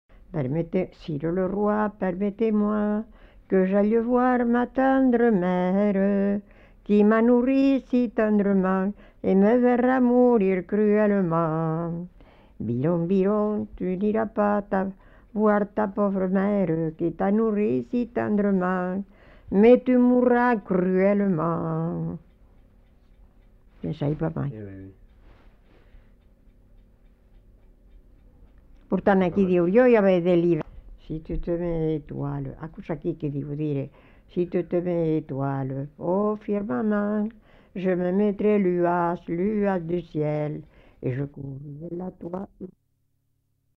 Aire culturelle : Haut-Agenais
Lieu : Fumel
Genre : chant
Effectif : 1
Type de voix : voix de femme
Production du son : chanté